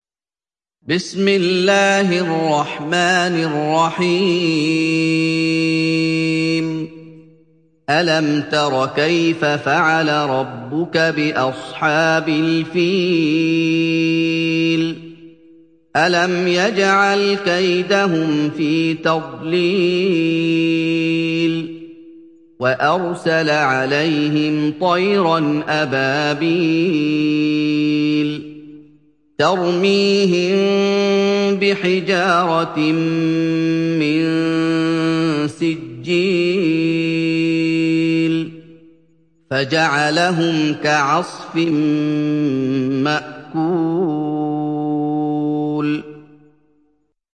تحميل سورة الفيل mp3 بصوت محمد أيوب برواية حفص عن عاصم, تحميل استماع القرآن الكريم على الجوال mp3 كاملا بروابط مباشرة وسريعة